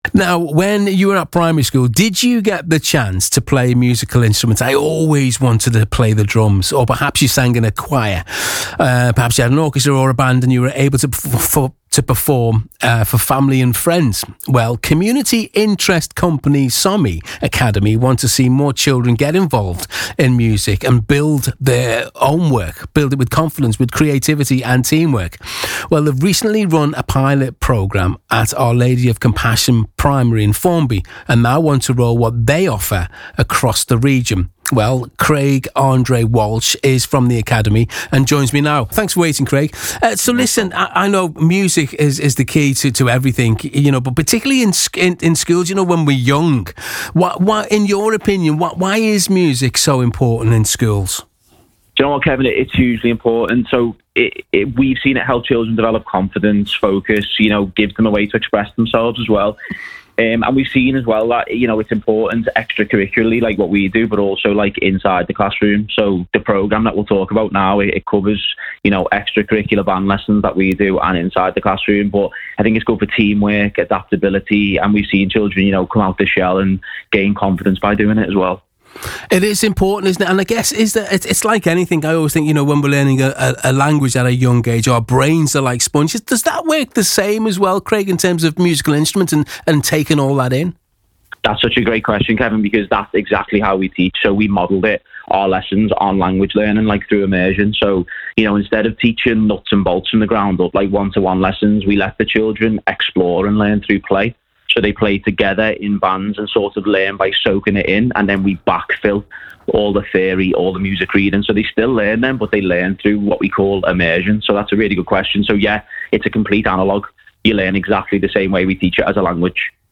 Why Music in Schools Matters – BBC Radio Merseyside Interview